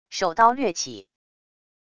手刀掠起wav音频